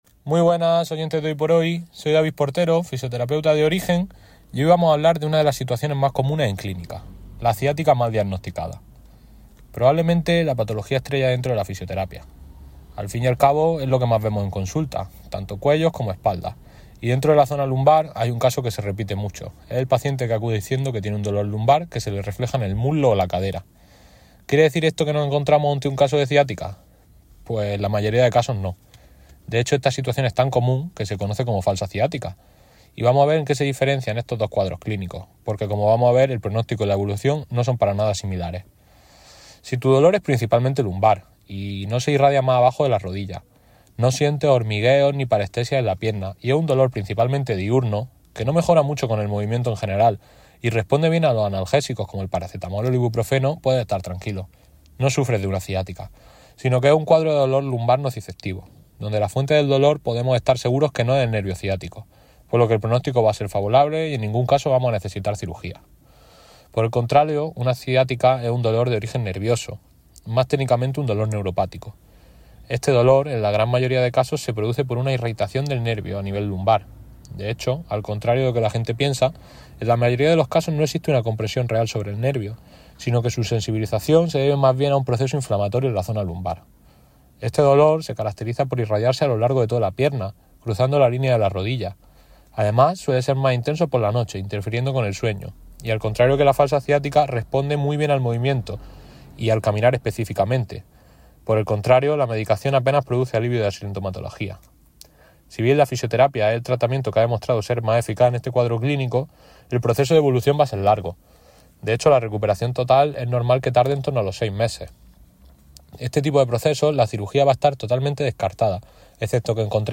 Este mes hemos participado en la radio compartiendo un consejo de fisioterapia para cuidar tu cuerpo en el día a día.